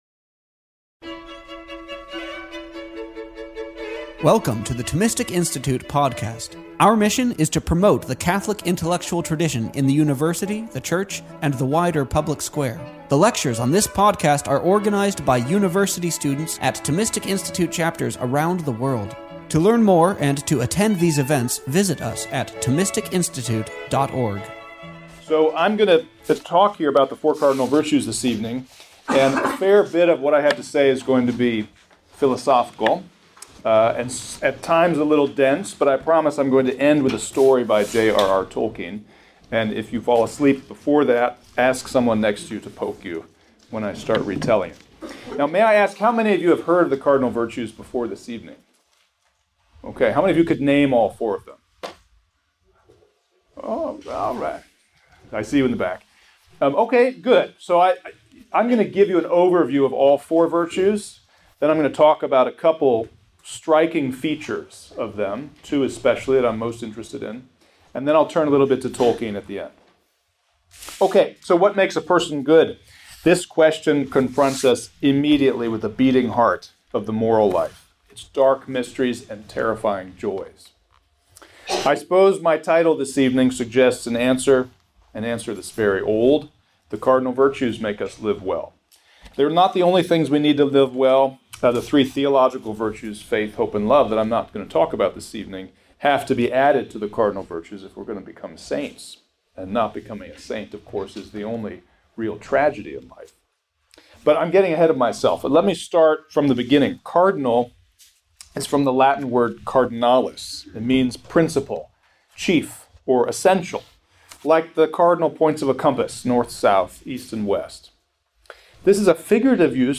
This lecture was given to a small student seminar at Duke University on October 5th, 2018.